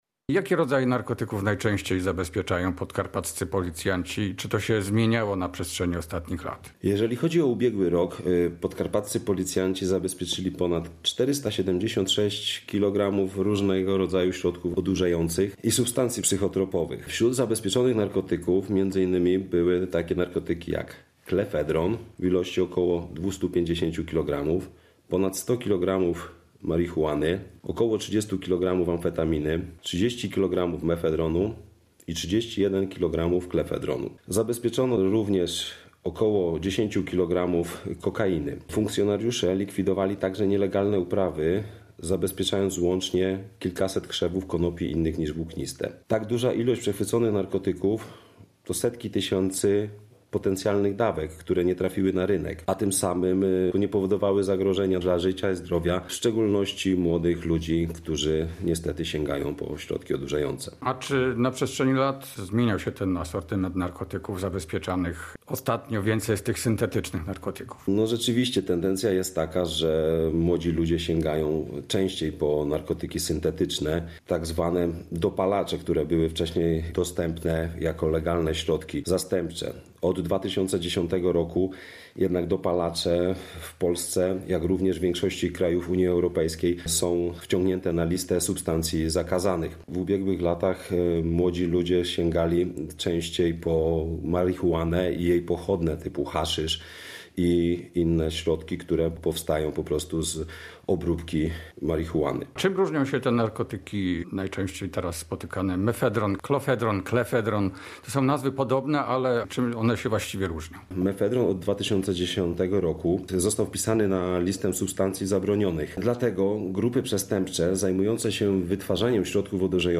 W „Wieczorze z kryminałem” rozmowa z oficerem operacyjnym nowego wydziału do spraw przestępczości narkotykowej w Komendzie Wojewódzkiej Policji w Rzeszowie.